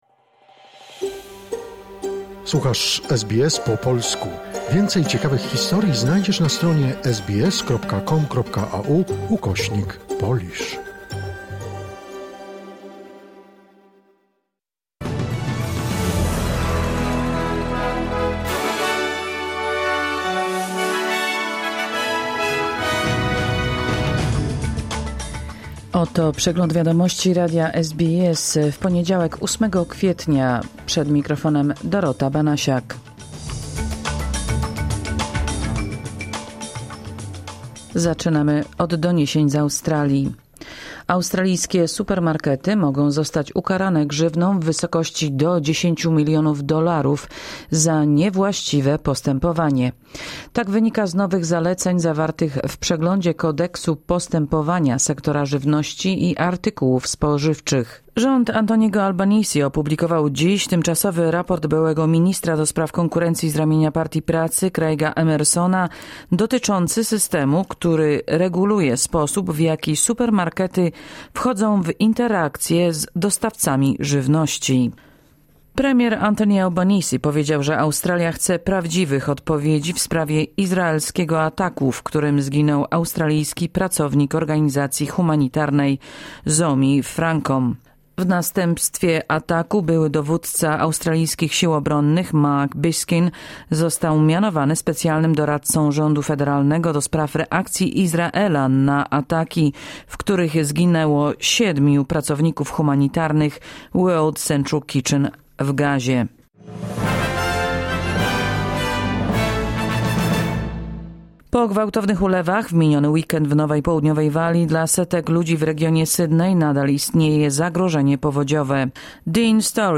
Wiadomości 8 kwietnia SBS News Flash